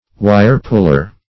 wirepuller \wirepuller\, Wire-puller \Wire"-pull`er\, n.